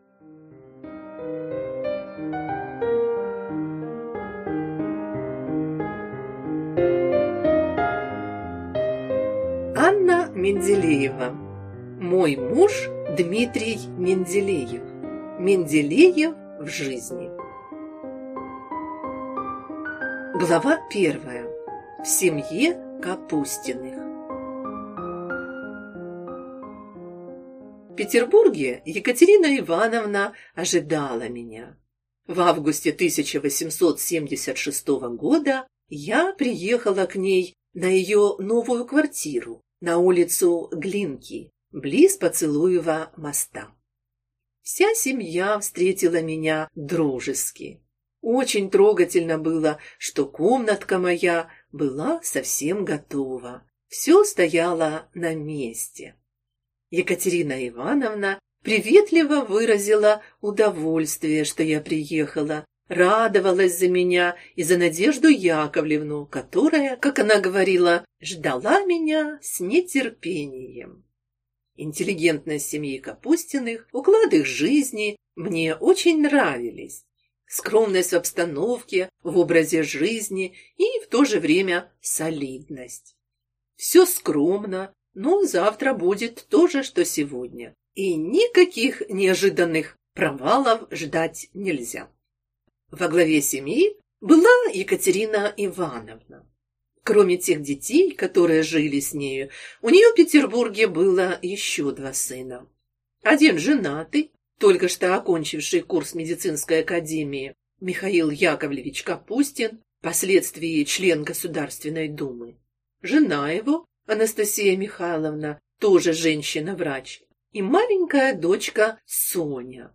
Аудиокнига Мой муж – Дмитрий Менделеев. Менделеев в жизни | Библиотека аудиокниг